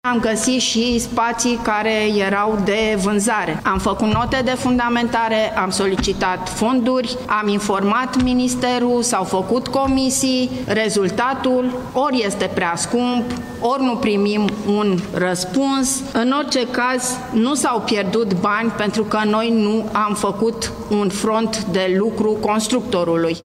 într-o conferință de presă